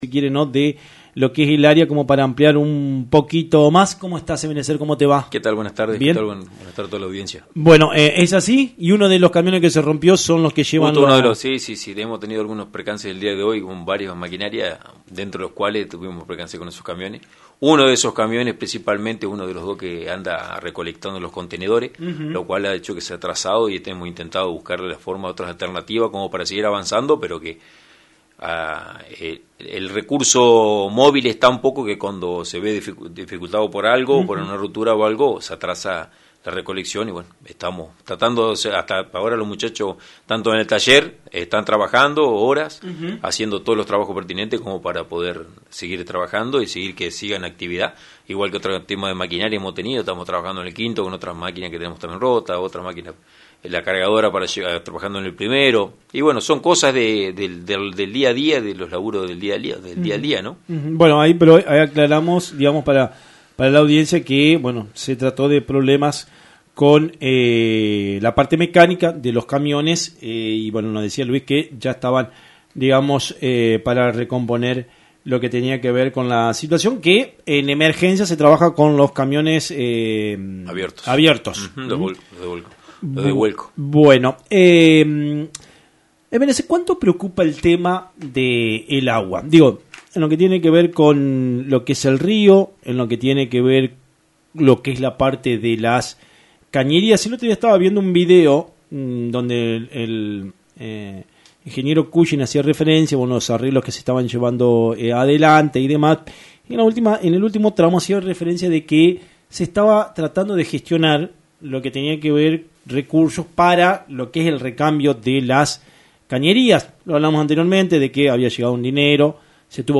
en el programa Radionoticias de FM 90.3 las actuales dificultades y proyectos en curso que enfrenta la ciudad. La problemática con la maquinaria, el suministro de agua y los esfuerzos para mejorar la infraestructura urbana, se posiciona como las principales preocupaciones.